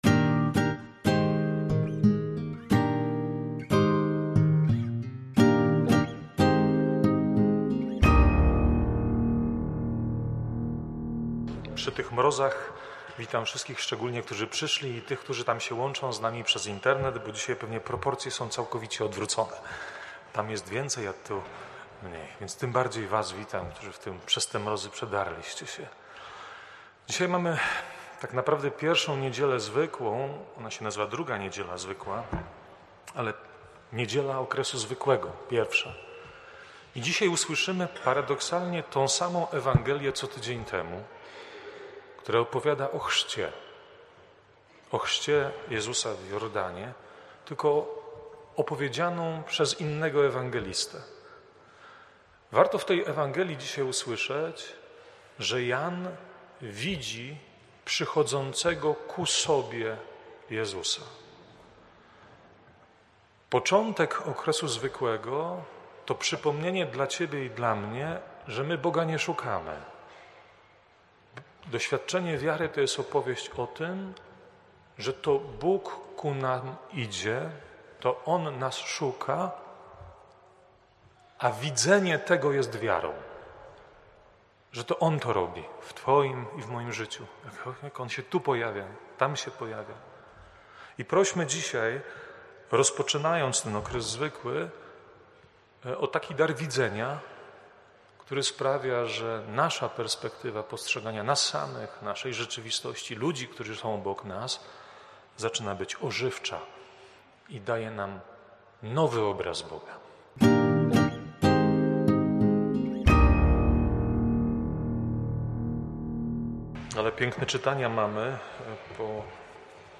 wprowadzenie do Liturgii, oraz homilia: